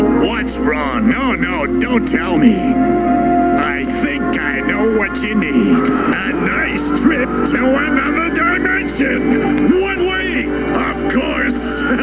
Nappa Speaks